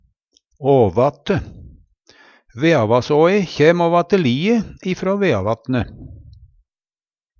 Tilleggsopplysningar Blir også sagt åvante
DIALEKTORD PÅ NORMERT NORSK åvate/åvante ovanfrå Eksempel på bruk Veavassåe kjæm åvate lie ifrå Veavatne.